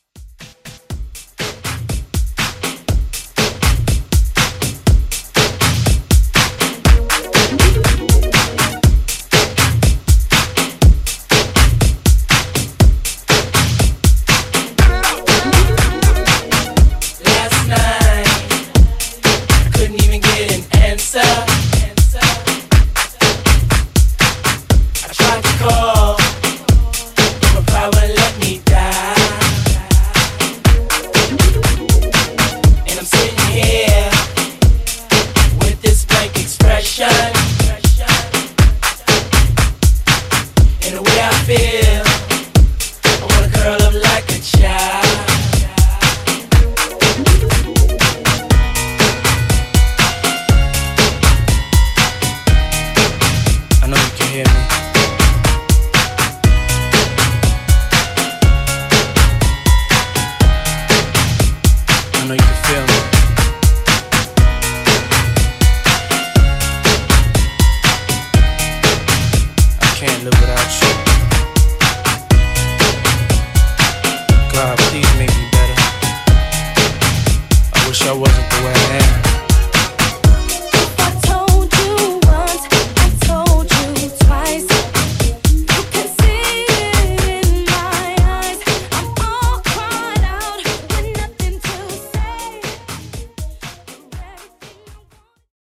Old Shool HipHop ReDrum